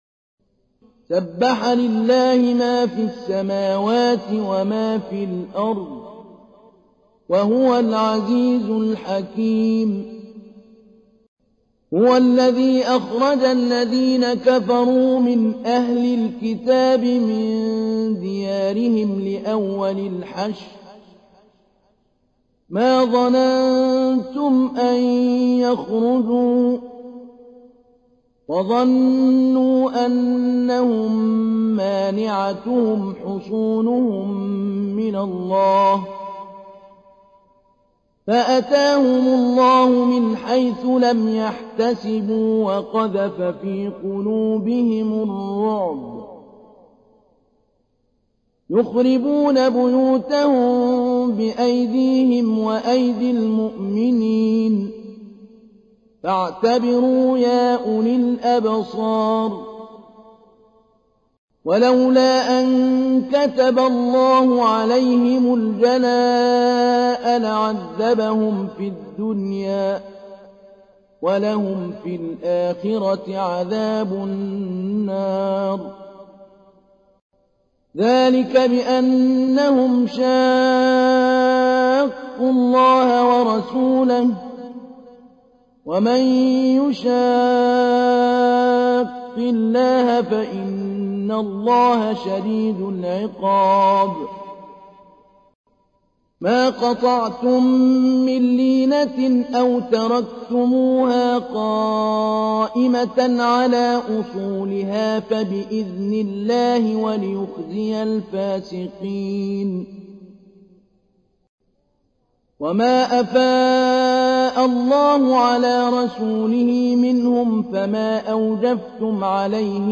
تحميل : 59. سورة الحشر / القارئ محمود علي البنا / القرآن الكريم / موقع يا حسين